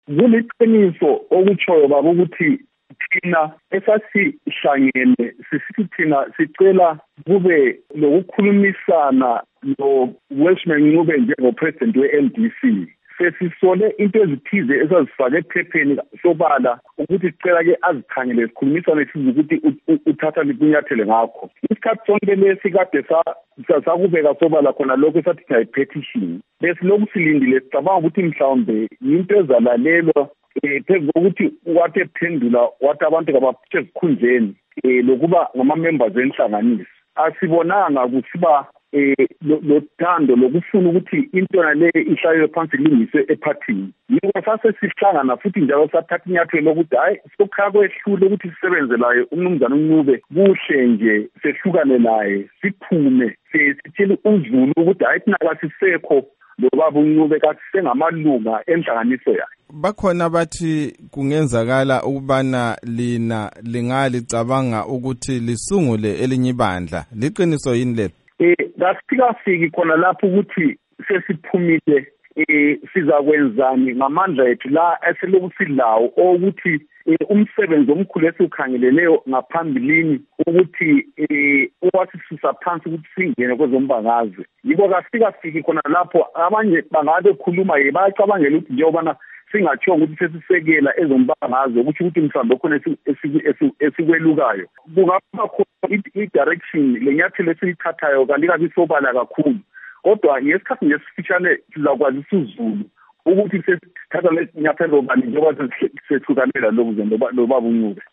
Ingxoxo loMnu. Moses Mzila Ndlovu